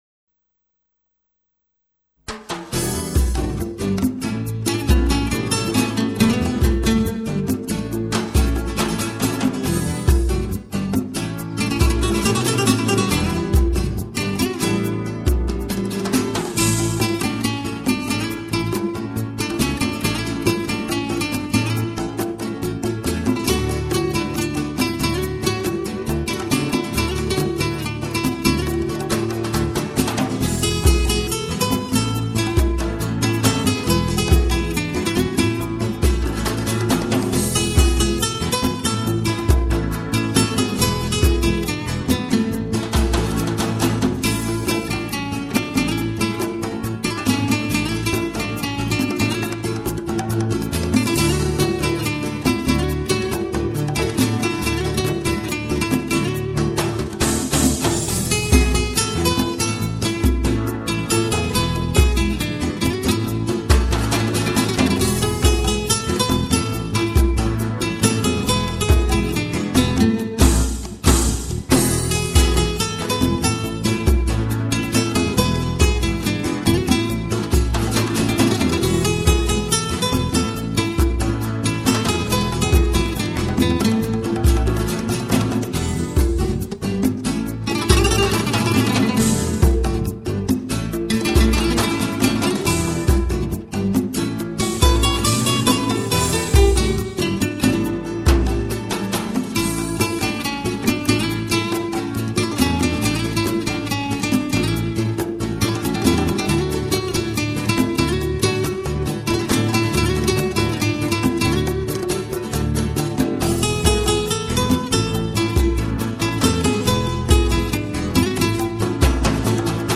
0293-吉他名曲佐安度.mp3